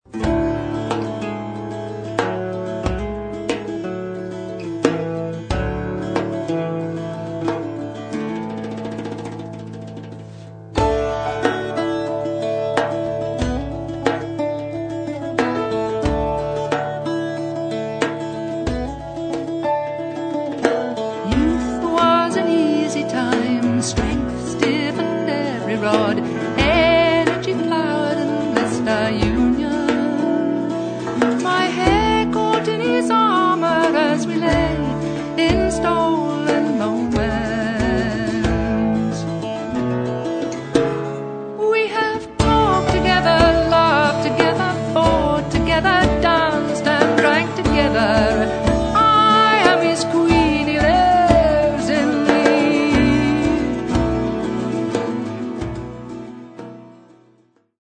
First part, 0:57 sec, mono, 22 Khz, file size: 297 Kb.